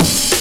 amen cymbal.wav